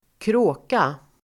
Uttal: [²kr'å:ka]